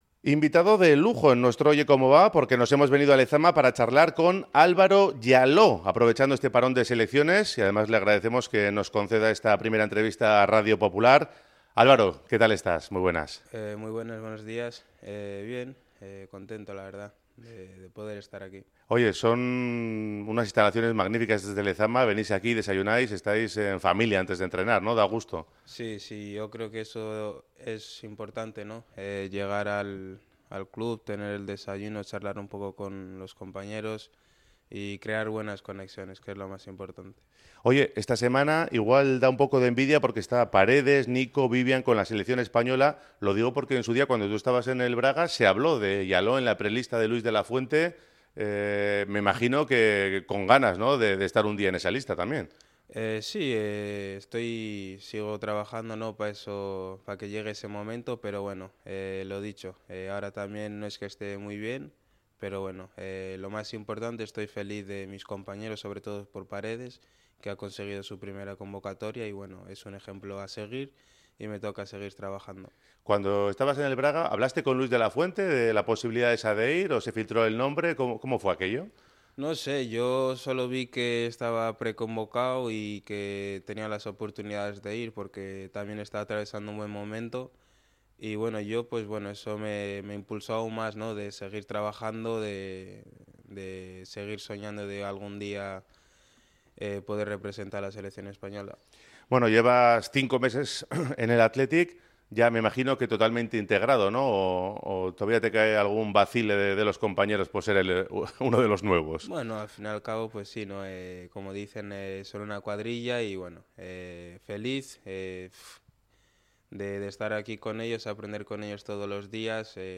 Primera entrevista que concede el jugador tras su fichaje por el Athletic